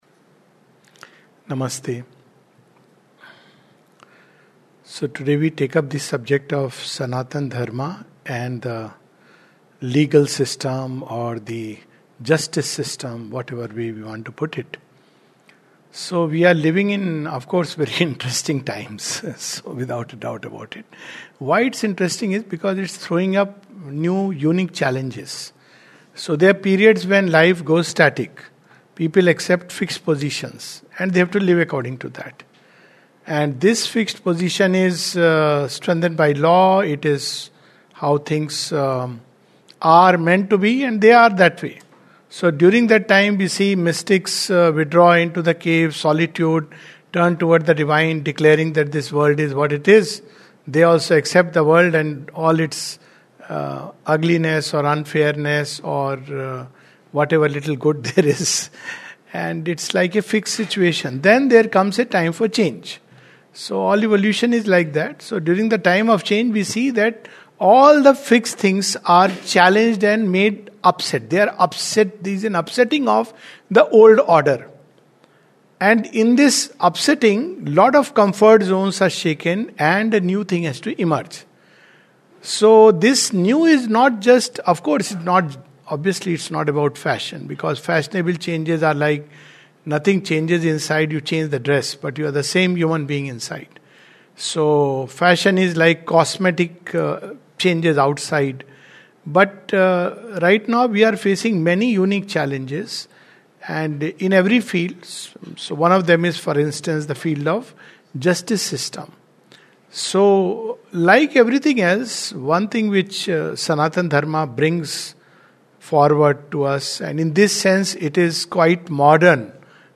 This talk takes up issues of law and justice in the light of Sanatan Dharma.